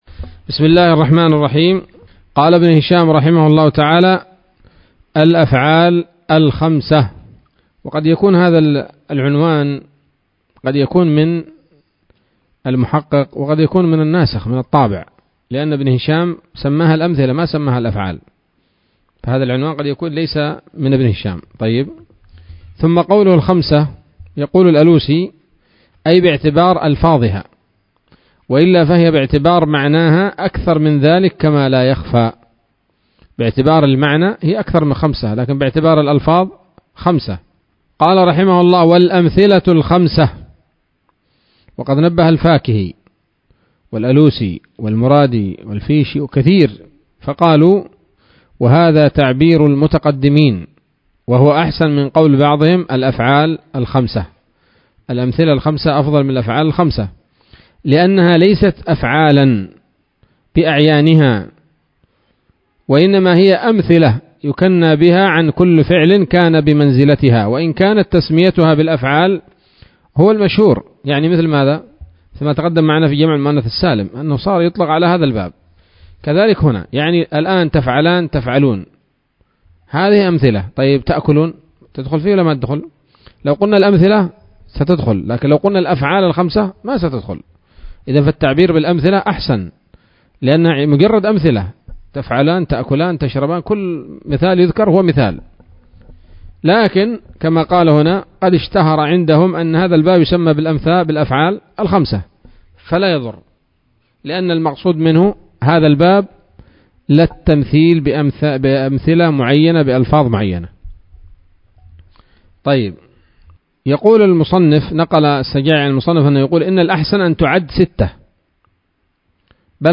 الدرس الثاني والعشرون من شرح قطر الندى وبل الصدى [1444هـ]